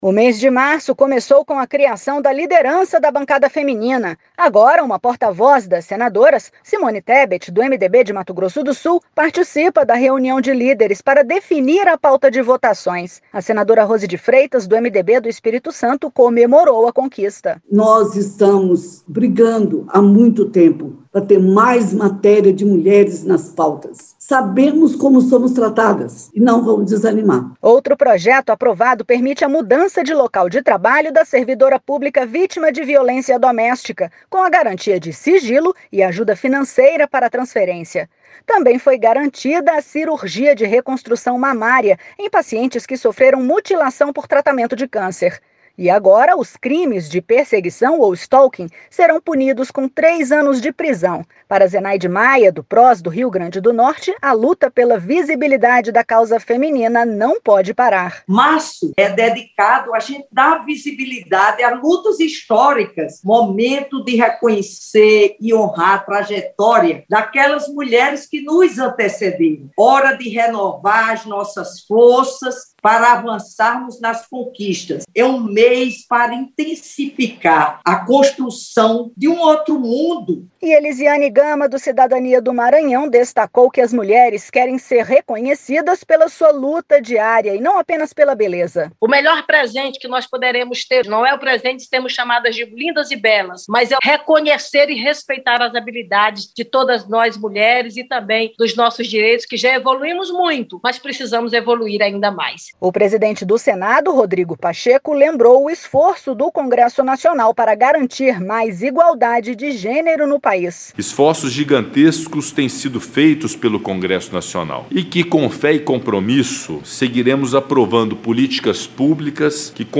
Senador Rodrigo Pacheco
Senadora Eliziane Gama
Senadora Rose de Freitas
Senadora Zenaide Maia